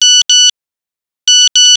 Tema: Re: Nokia SMS garsas
> Kazi gal kas uzmatet originalu nokia sms atejimo garsa ? :) I gudrafona noretus ikist.